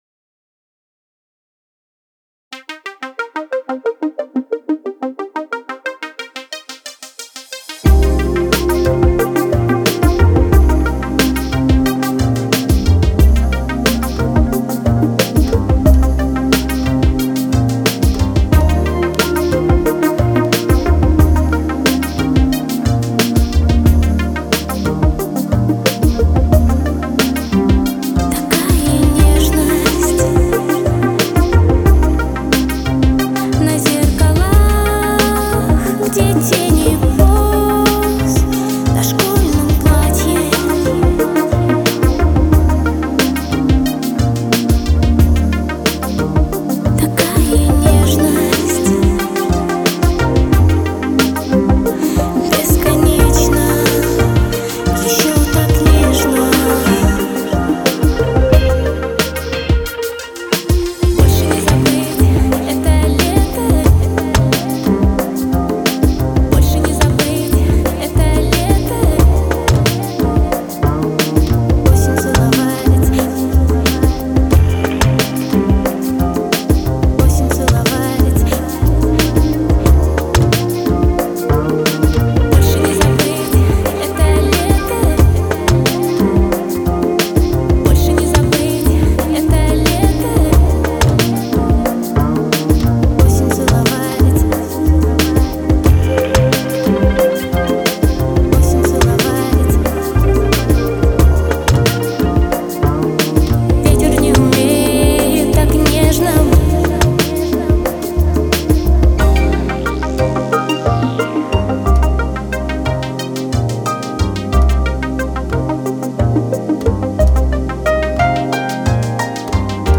remix aciid jazz